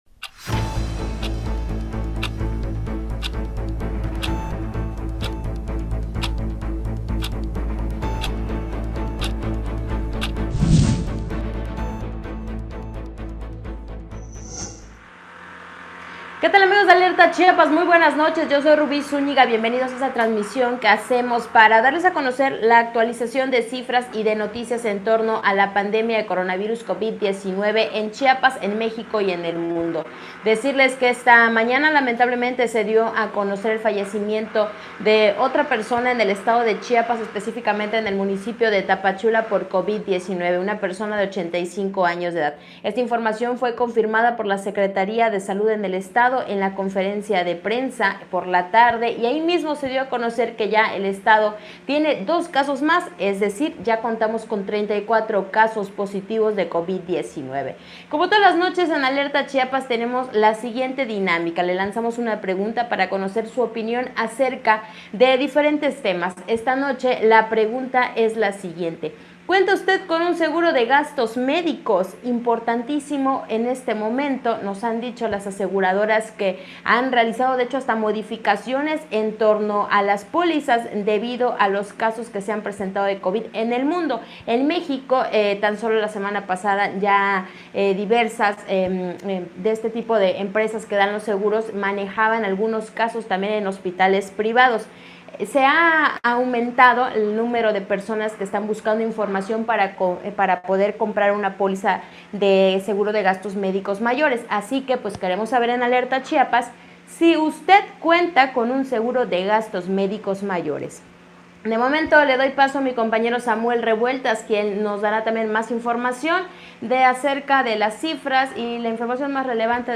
TRANSMISIÓN ESPECIAL DE ALERTA CHIAPAS POR LA PANDEMIA DE CORONAVIRUS.
LUGAR: TUXTLA GUTIÉRREZ